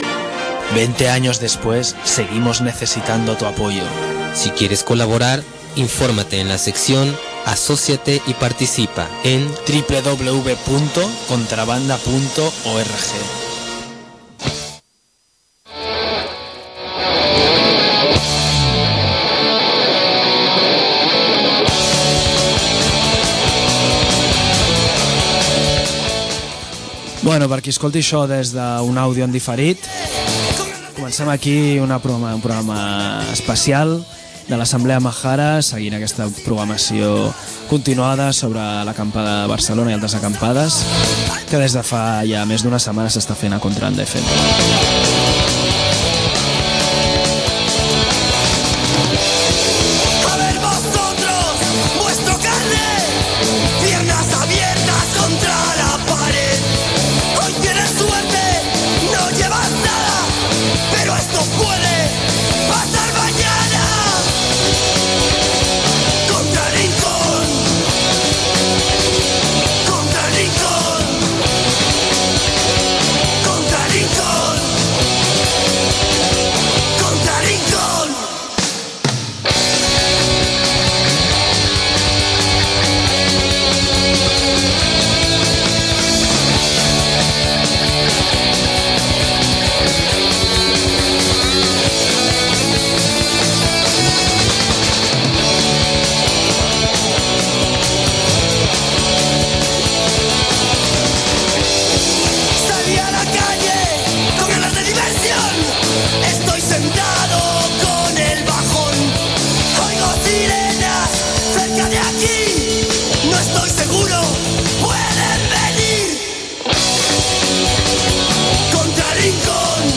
Ens truquen des de la Plaça dels Àngels on es commemora la Naqba en una jornada organitzada per la coordinadora d’entitats catalanes Amb Palestina al Cor.